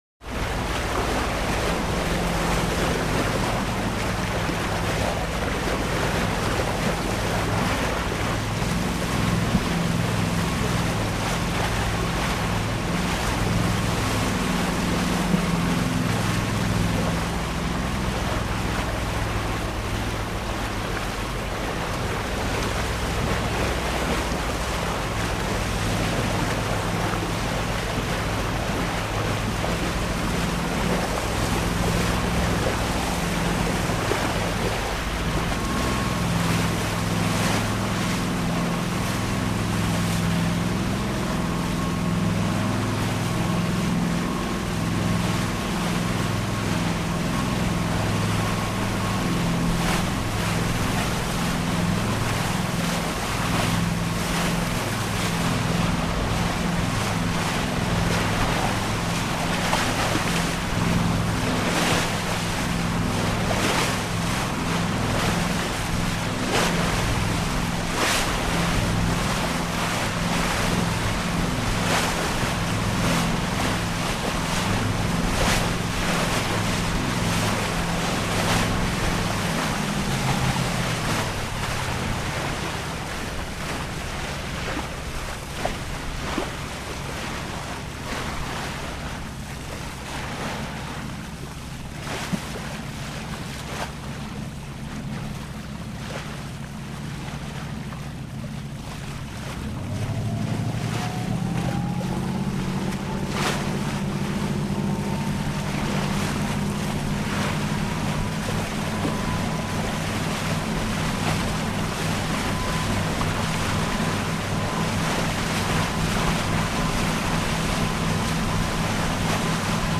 Power Yacht 4; Cruise Steady, Front Of Boat Perspective, With Bow Wash.